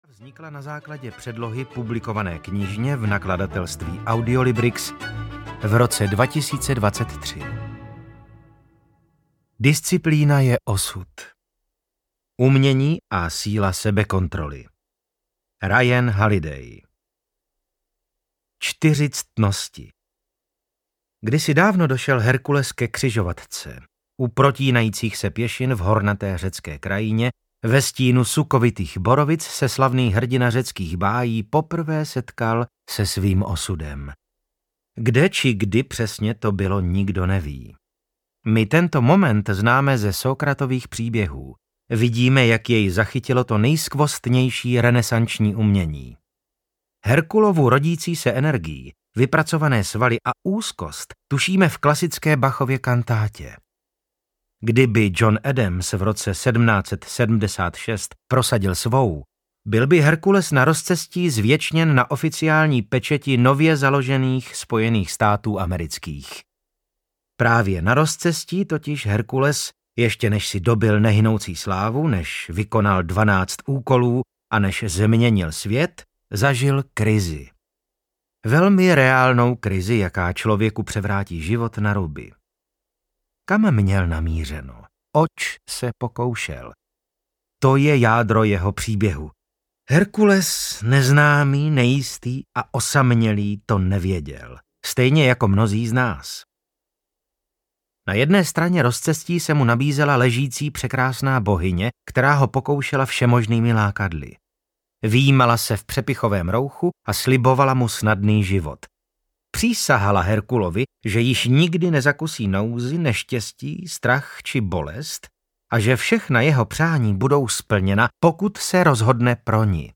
Disciplína je osud audiokniha
Ukázka z knihy
• InterpretVasil Fridrich